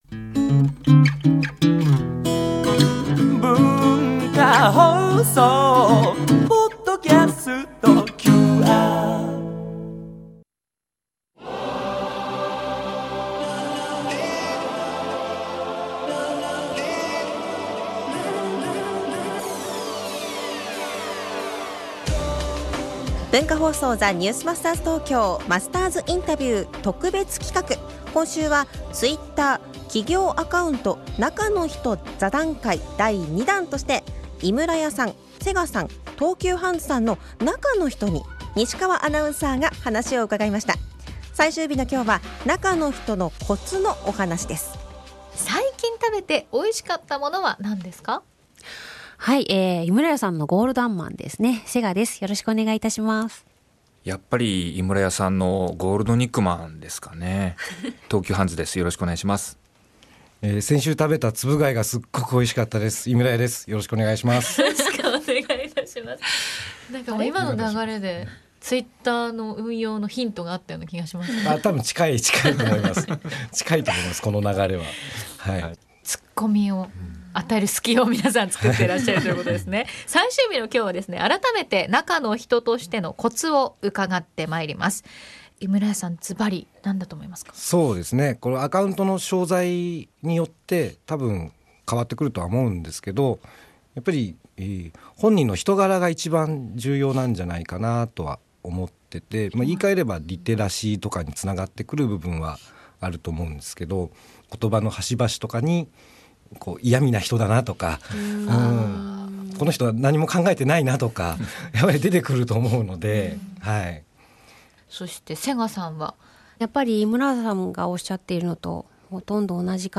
（月）～（金）AM7：00～9：00　文化放送にて生放送！